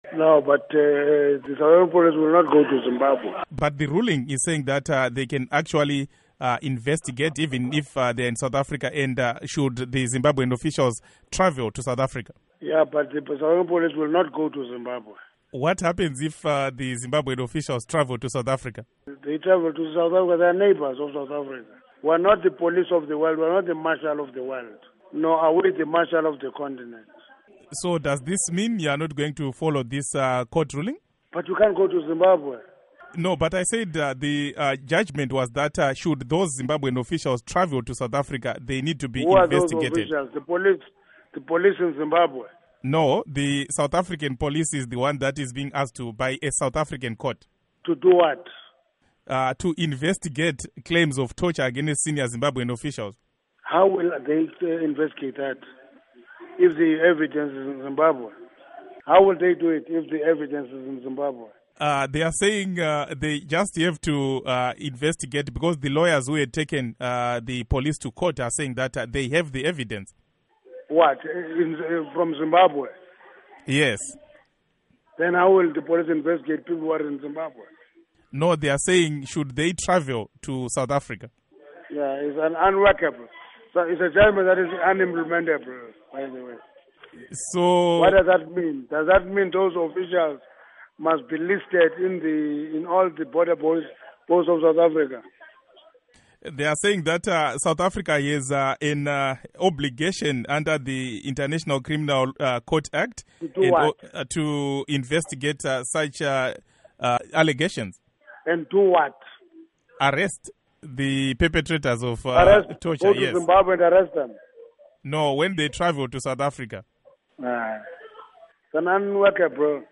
Interview With Gwede Mantashe